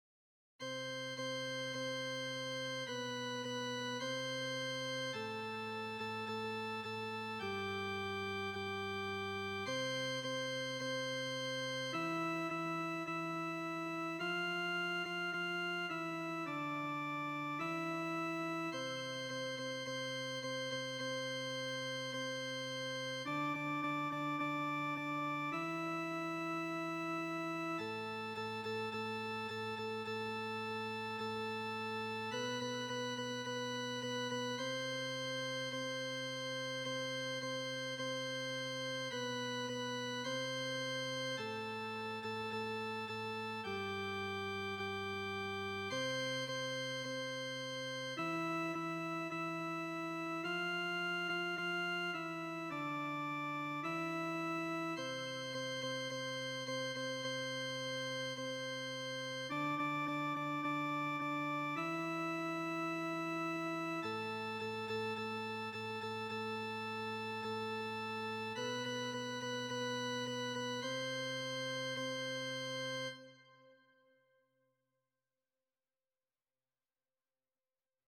Répétition de la pièce musicale
Répétition SATB par voix
Tenor
Aimons-nous_tenor.mp3